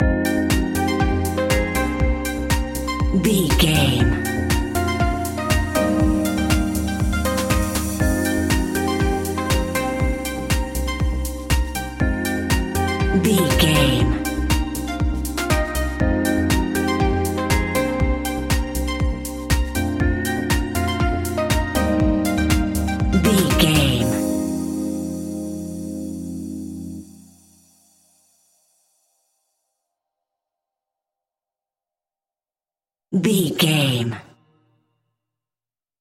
Aeolian/Minor
uplifting
driving
energetic
funky
synthesiser
drum machine
electro house
synth leads
synth bass